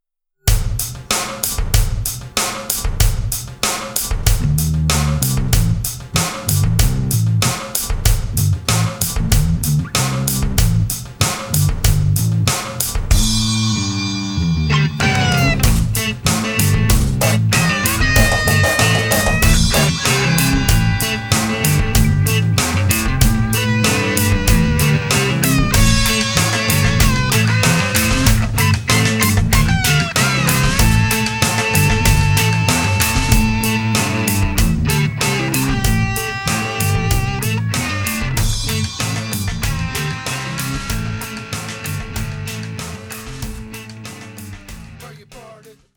perfect for Website background music or YouTube videos